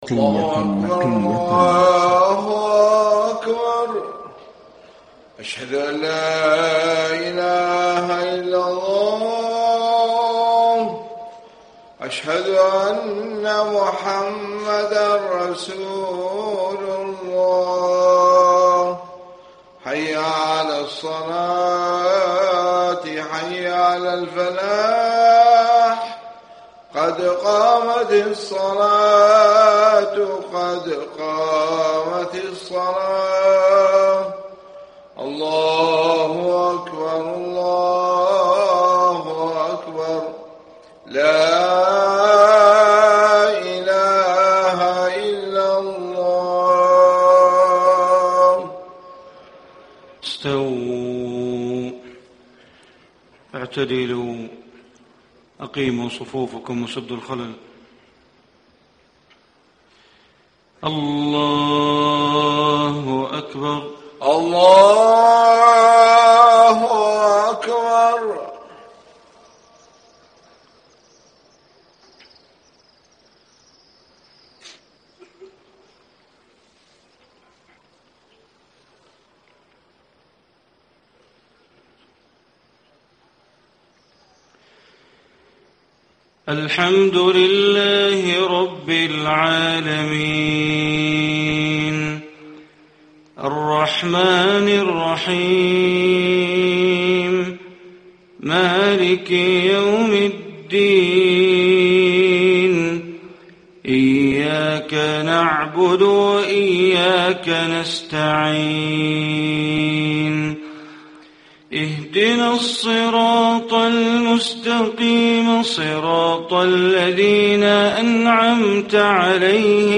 صلاة الفجر 5-6-1435 ما تيسر من سورتي فصلت والأحقاف > 1435 🕋 > الفروض - تلاوات الحرمين